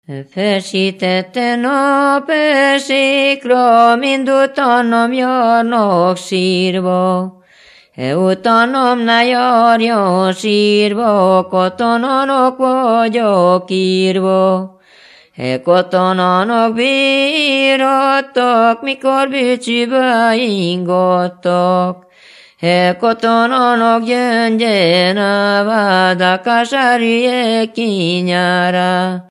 Moldva és Bukovina - Moldva - Klézse
ének
Műfaj: Katonadal
Stílus: 8. Újszerű kisambitusú dallamok